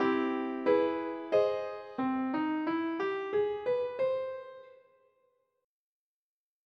augmented chords example
augmented chords